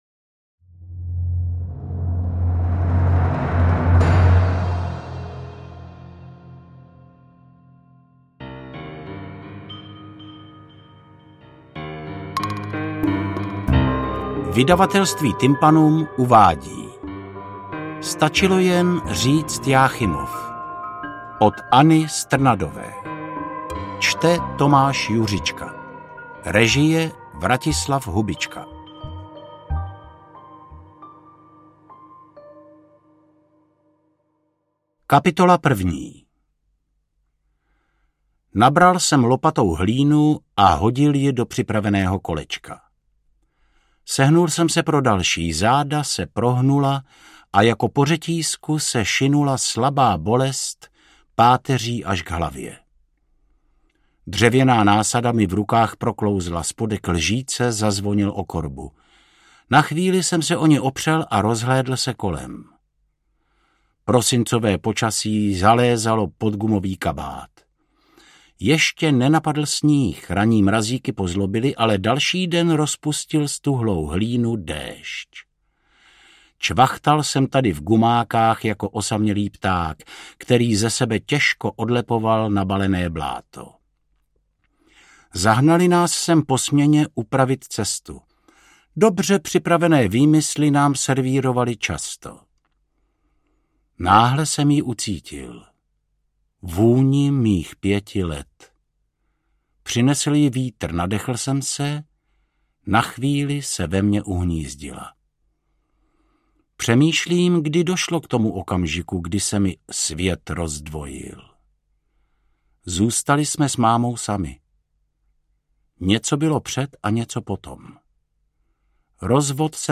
AudioKniha ke stažení, 27 x mp3, délka 7 hod. 42 min., velikost 424,0 MB, česky